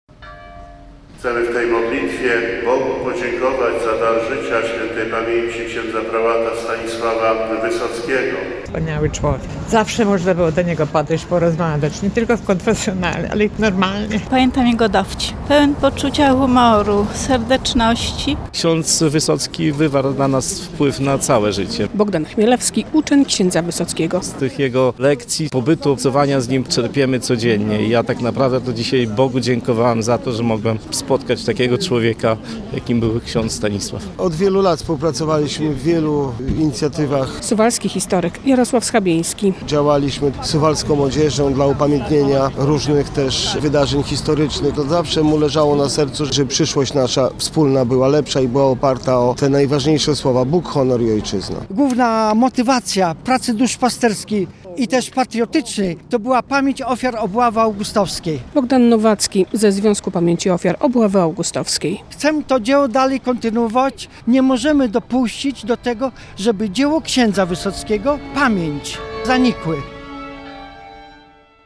W Konkatedrze św. Aleksandra na mszy świętej zebrali się księża, harcerze, przedstawiciele służb mundurowych, samorządowcy, współpracownicy i uczniowie oraz parafianie.